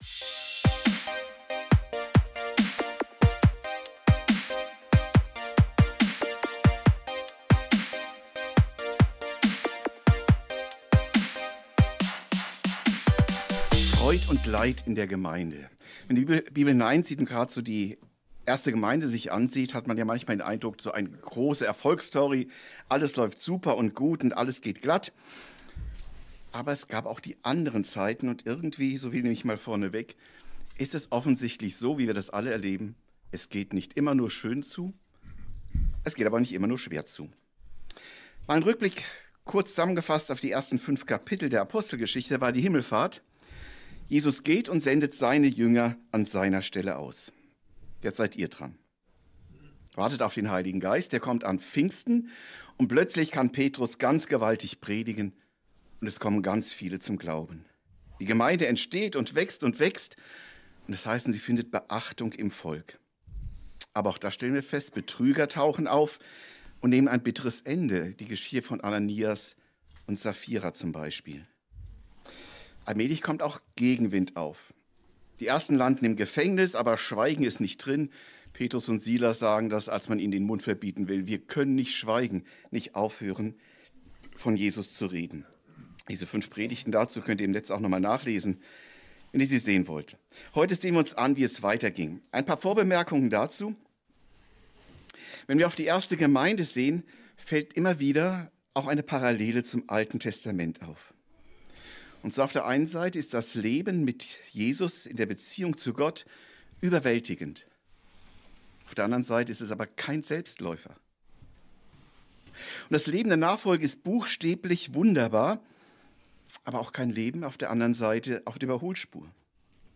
Freud und Leid in der ersten Gemeinde ~ Predigten u. Andachten (Live und Studioaufnahmen ERF) Podcast